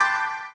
piano_G5_D5_G4_2.ogg